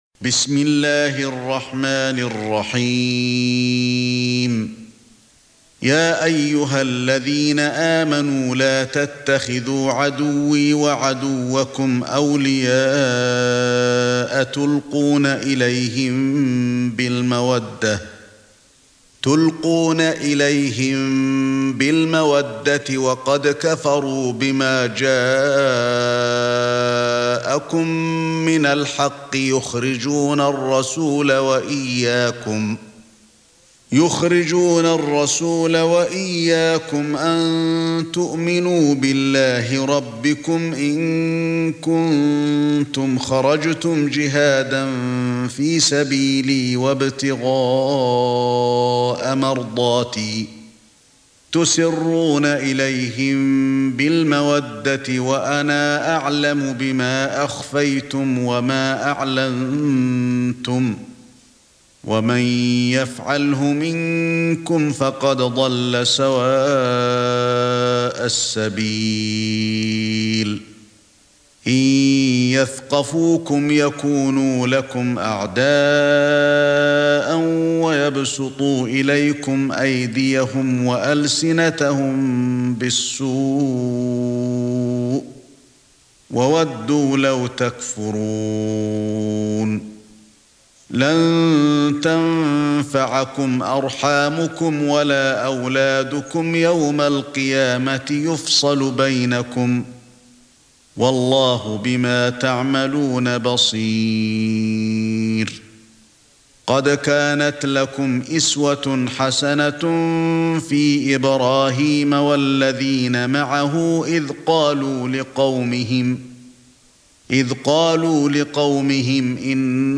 سورة الممتحنة ( برواية قالون ) > مصحف الشيخ علي الحذيفي ( رواية قالون ) > المصحف - تلاوات الحرمين